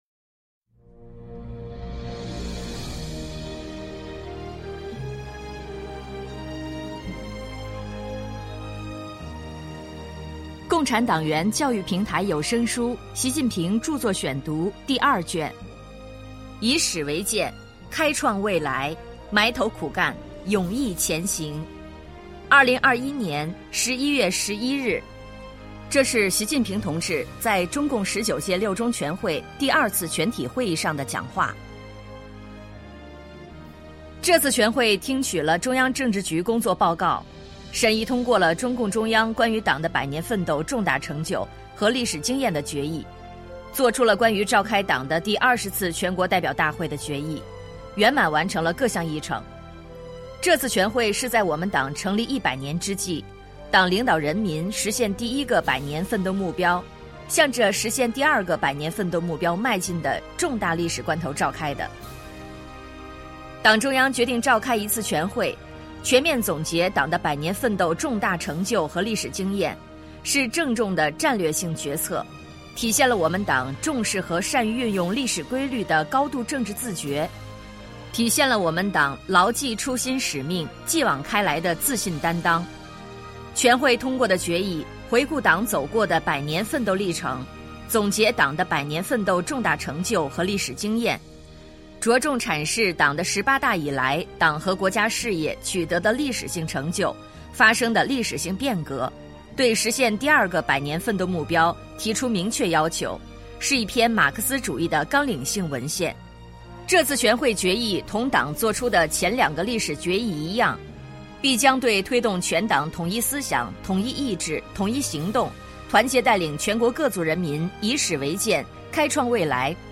主题教育有声书 《习近平著作选读》第二卷（78）.mp3